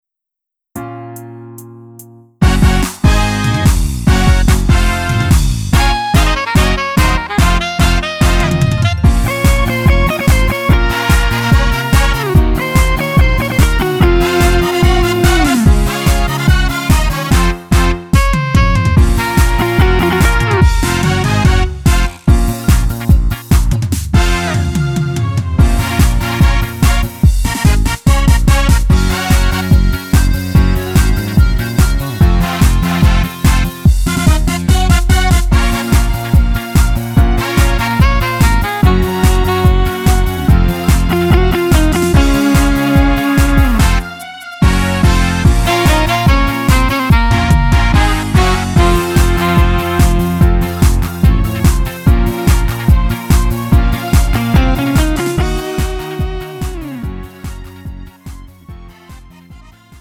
음정 -1키 3:16
장르 가요 구분 Lite MR
Lite MR은 저렴한 가격에 간단한 연습이나 취미용으로 활용할 수 있는 가벼운 반주입니다.